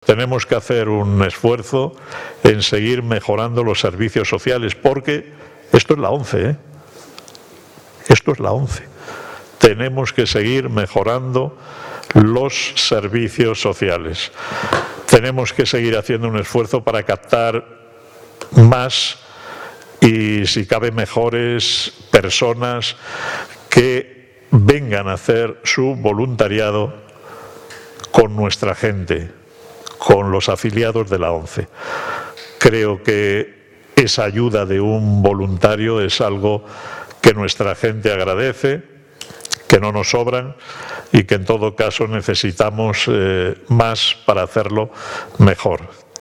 El CCG fue clausurado por el presidente del Grupo Social ONCE, Miguel Carballeda, quien durante su intervención hizo alusión, entre otros temas, a la “buena situación económica” actual del Grupo, aunque apeló a la “prudencia para saber gestiona los éxitos que estamos teniendo y